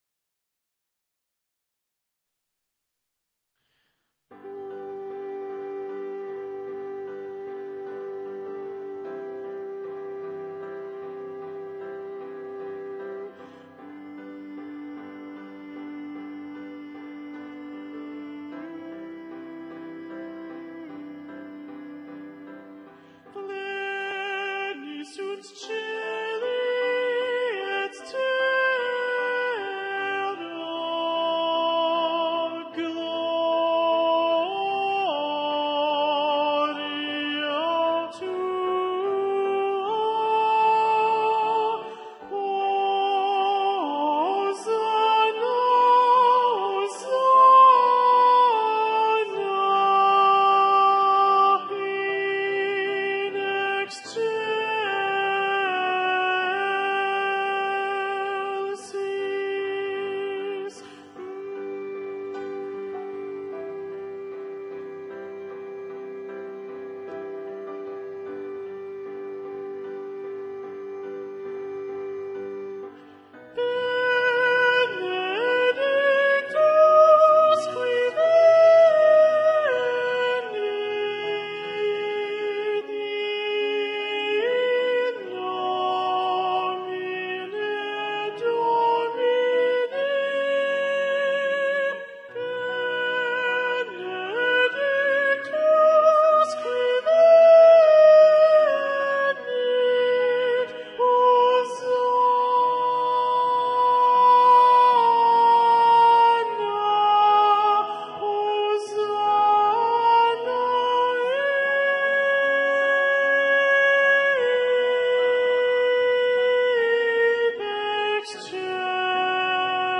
Répétition SATB par voix,  (mp3 sur le site, accès direct)
Soprano 2
Ground Soprano 2 Solo.mp3